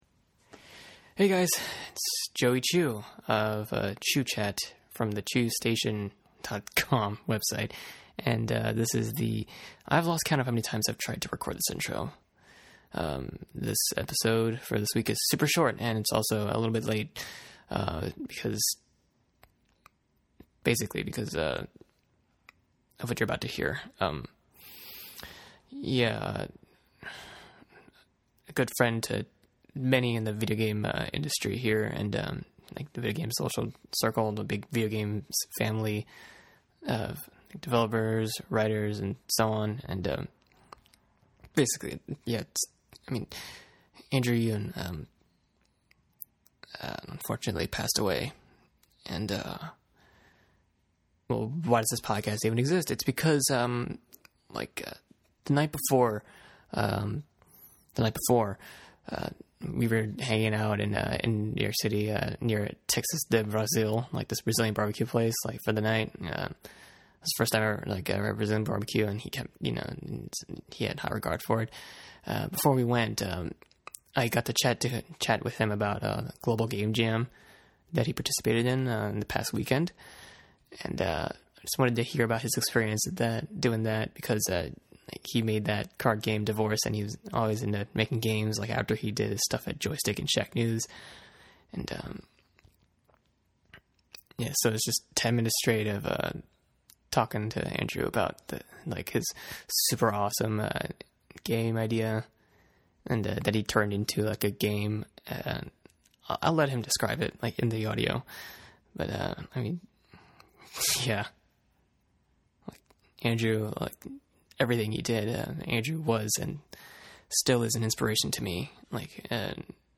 He offered his insights and answered questions with his signature touch of humor.